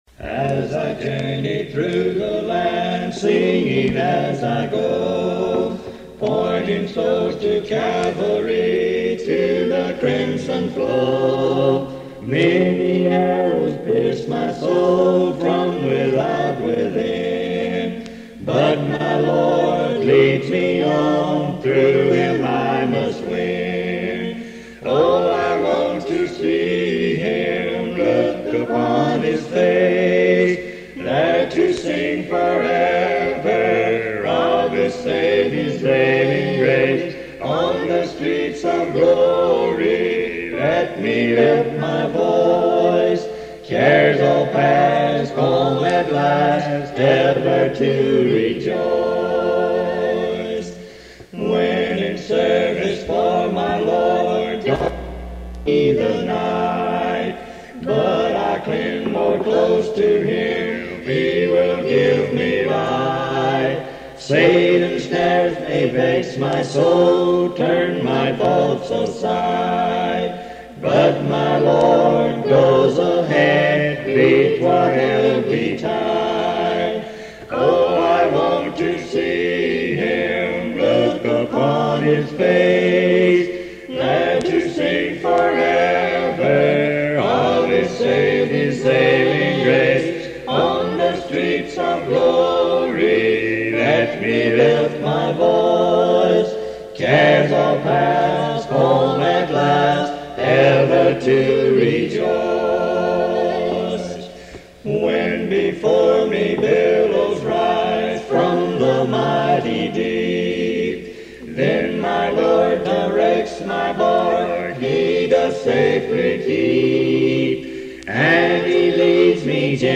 Family Singing